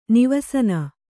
♪ nivasana